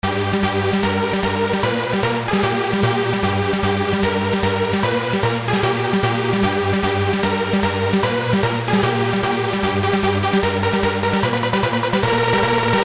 硬合成器的建立
描述：与鼓声一起使用时，很适合在曲目中真正达到高潮。
Tag: 150 bpm Hardcore Loops Synth Loops 2.15 MB wav Key : Unknown